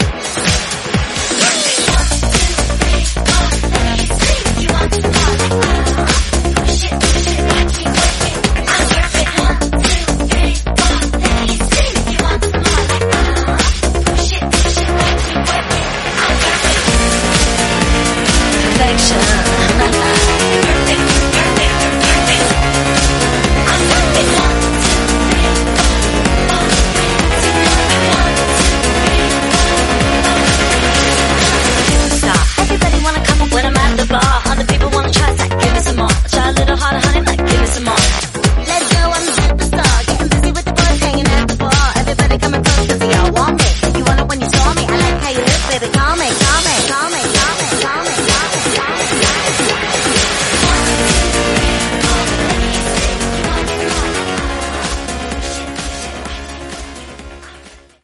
Genre: 2000's
Clean BPM: 126 Time